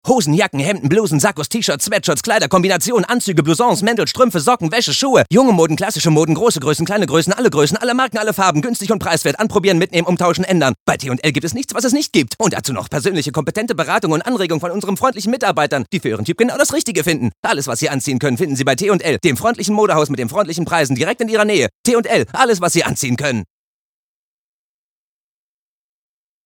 plakativ
Mittel plus (35-65)
Commercial (Werbung)